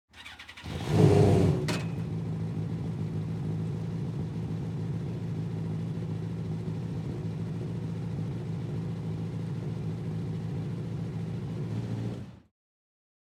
344 Audio - Sprint Car Racing
Sprint Car, Start, Rev, Idle, Off, Mid RPM.ogg